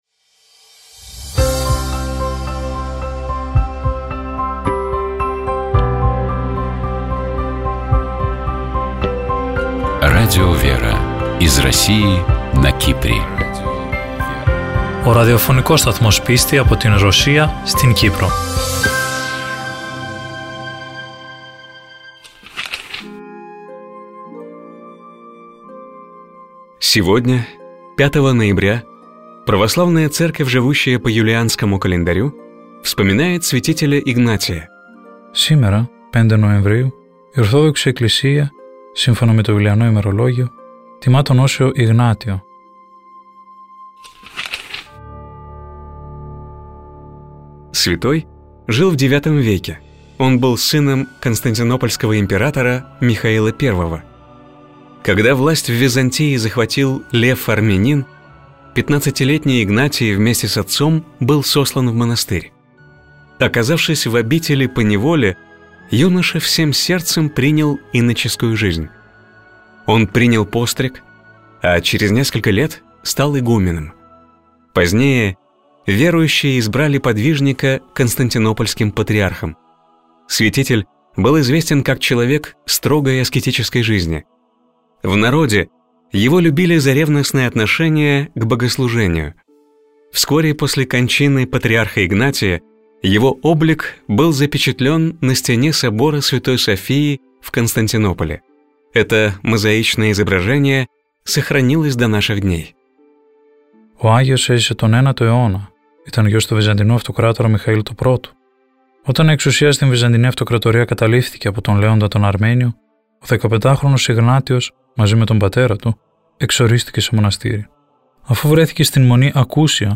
У нас в гостях был наместник московского Донского монастыря, епископ Бронницкий Парамон.